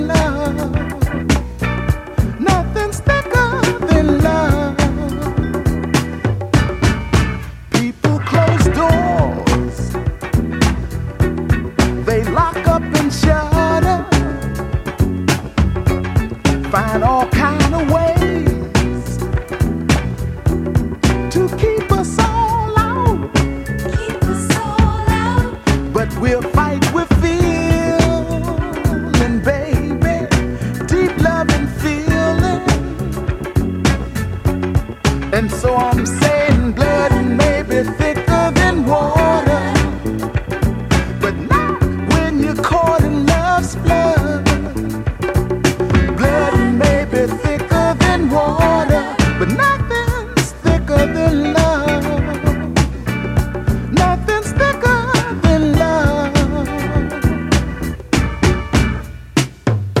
ジャンル(スタイル) SOUL / FUNK